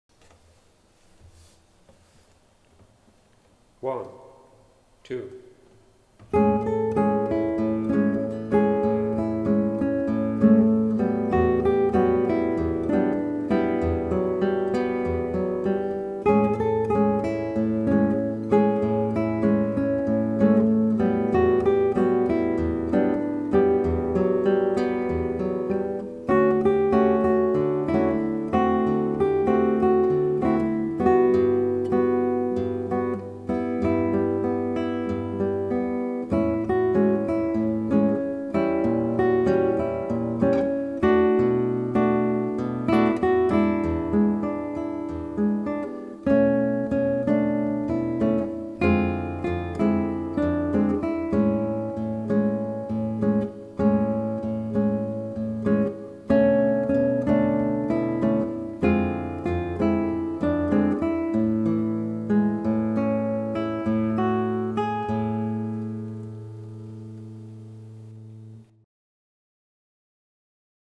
Duo-Version in verlangsamter Geschwindigkeit